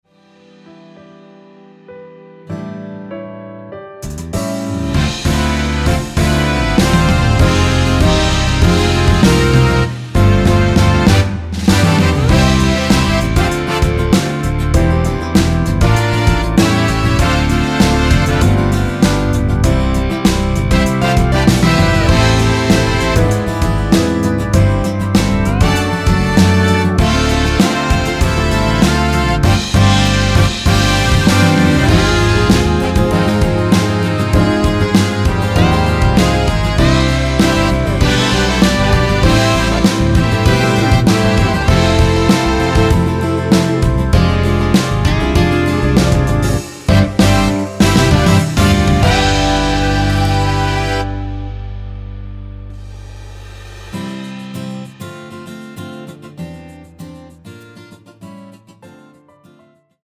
MR입니다.
미리듣기는 “후 살며시 네가 불어와” 부터 시작됩니다.
앞부분30초, 뒷부분30초씩 편집해서 올려 드리고 있습니다.
중간에 음이 끈어지고 다시 나오는 이유는